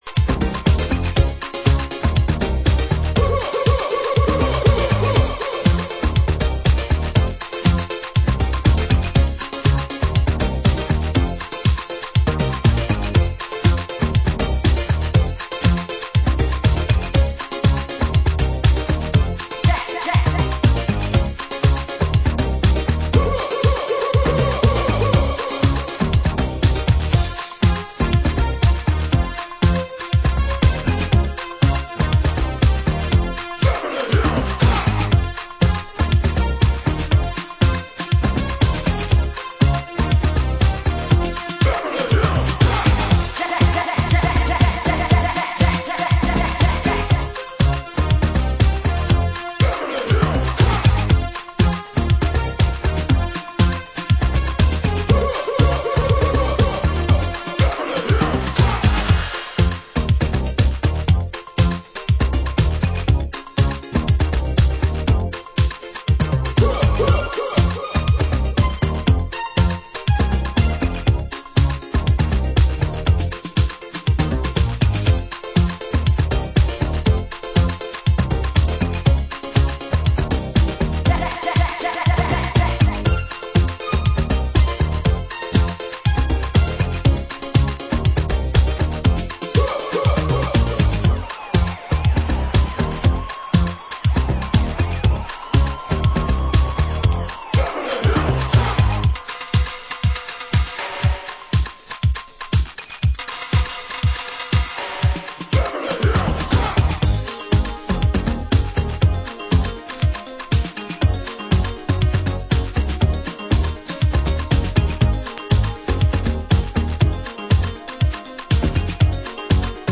Genre: Synth Pop